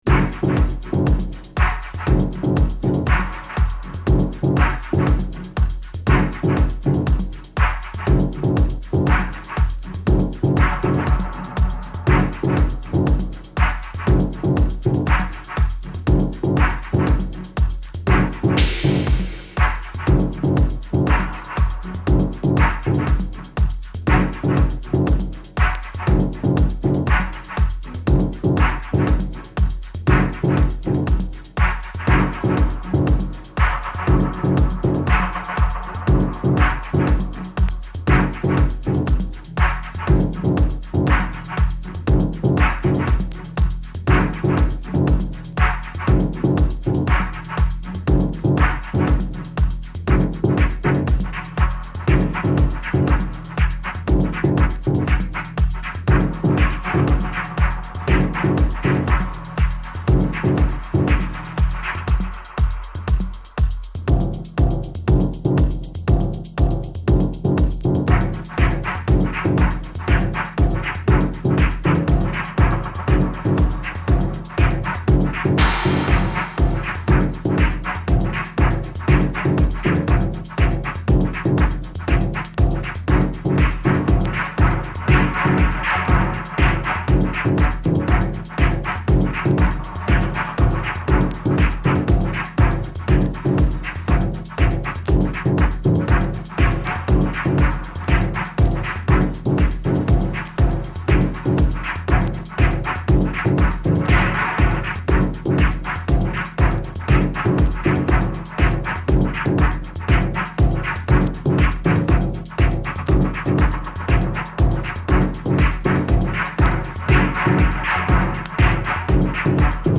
House / Techno